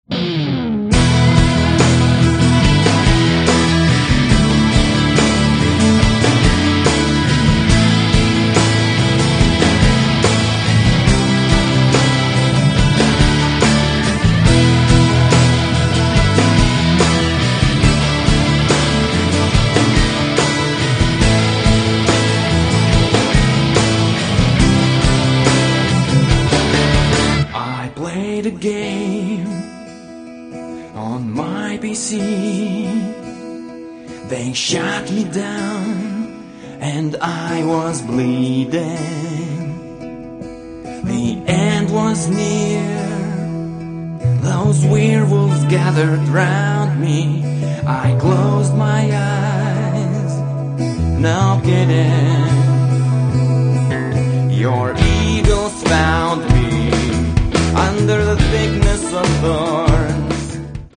krikščioniško roko